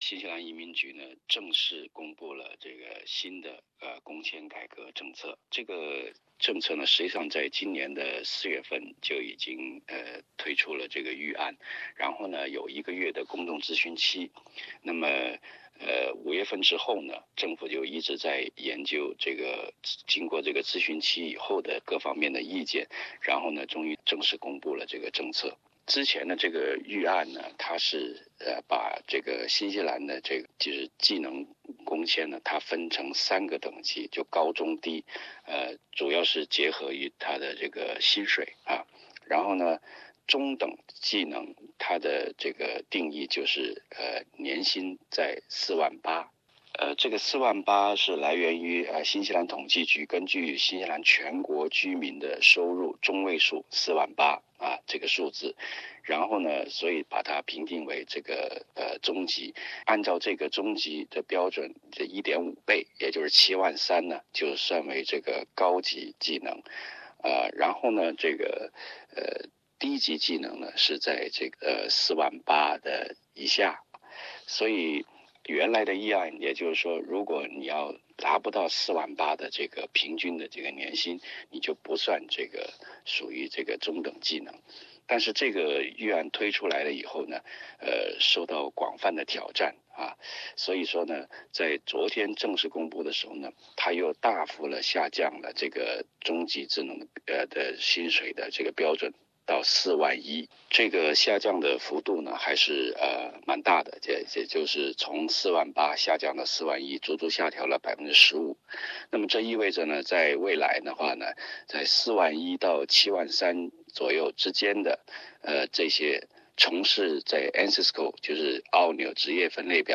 本节目为嘉宾观点，仅供参考。